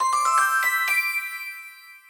tinkerbell.mp3